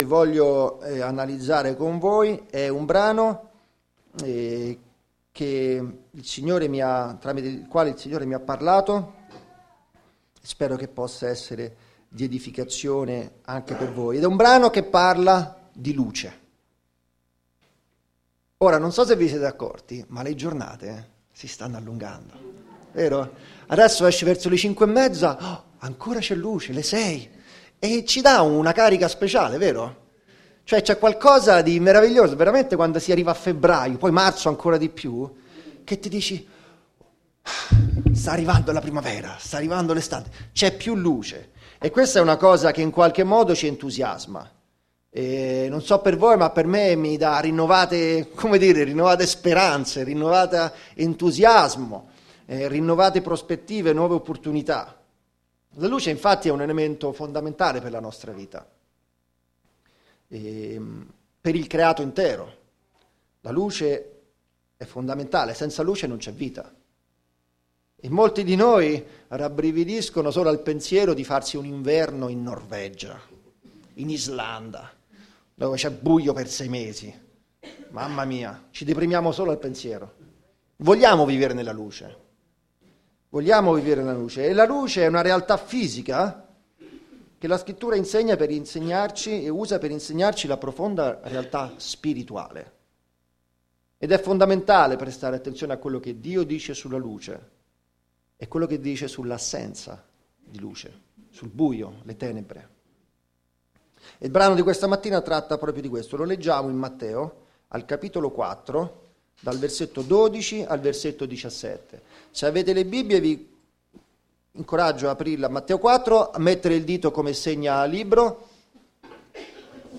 Sermoni della domenica